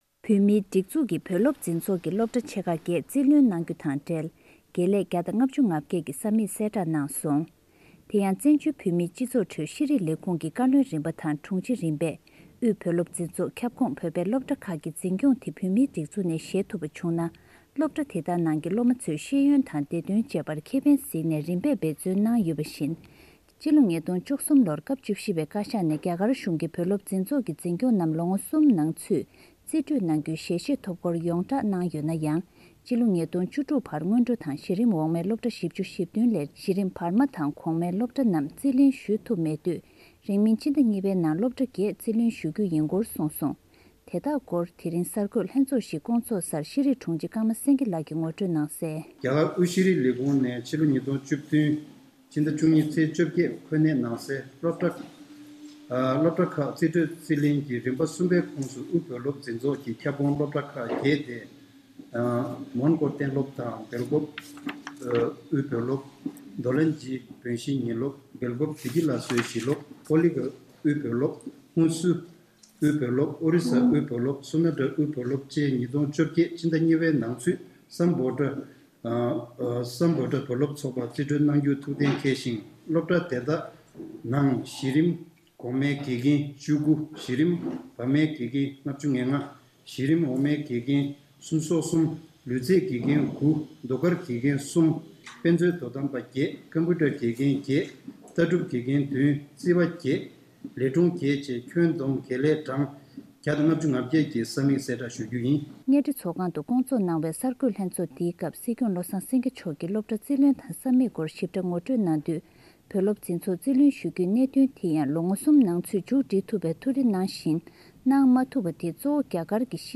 ཤེས་རིག་ལས་ཁུངས་ཀྱི་གོ་སྒྲིག་འོག་དབུས་བོད་སློབ་འཛིན་ཚོགས་ཁྱབ་ཁོངས་སློབ་གྲྭ་ཁག་བརྒྱད་ཕྱི་ལོ་ ༢༠༡༨ ཕྱི་ཟླ་ ༢ ནང་ཚུད་སྃ་བྷོ་ཌ་བོད་སློབ་ཚོགས་པར་རྩིས་སྤྲོད་གནང་གཏན་འཁེལ་བ་དང་སློབ་ཁག་དེ་དག་ནང་དགེ་རྒན་ལས་བྱེད་གྲངས་ ༡༥༨ ཀྱི་ས་དམིགས་སྟོང་པ་ཡོད་པའི་སྐོར་དཔལ་ལྡན་སྲིད་སྐྱོང་བློ་བཟང་སེངྒེ་མཆོག་གིས་དབུ་བཞུགས་ཐོག་གསར་འགོད་ལྷན་ཚོགས་བརྒྱུད་གསལ་བསྒྲགས་ཤིག་གནང་ཡོད་པའི་སྐོར་